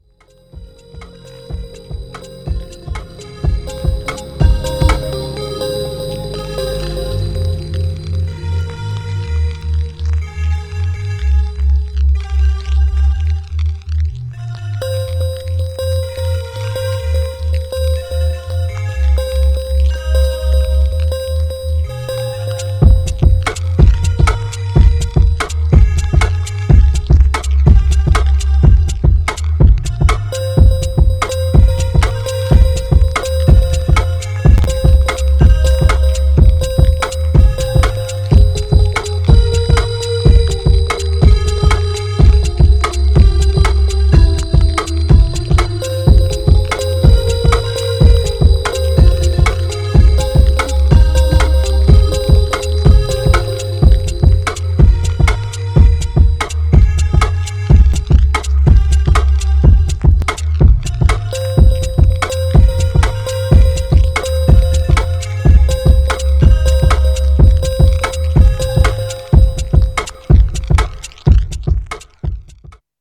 Styl: Dub/Dubstep, House, Techno, Breaks/Breakbeat